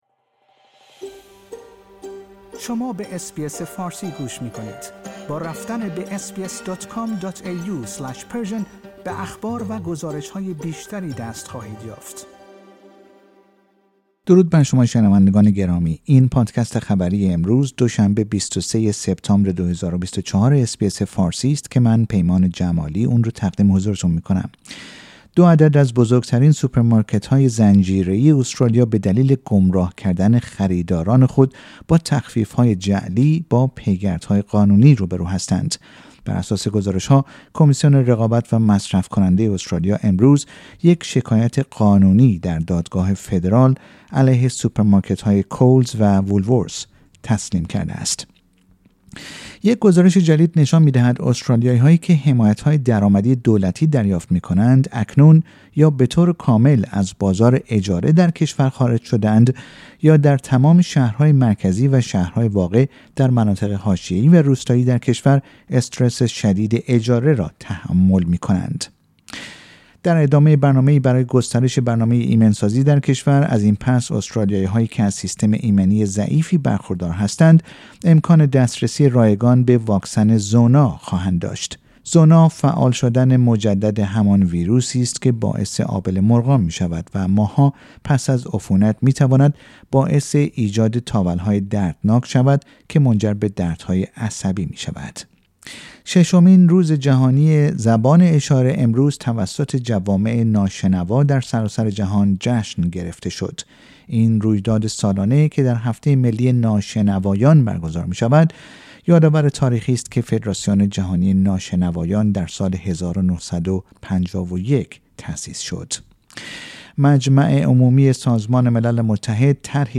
در این پادکست خبری مهمترین اخبار استرالیا در روز دوشنبه ۲۳ سپتامبر ۲۰۲۴ ارائه شده است.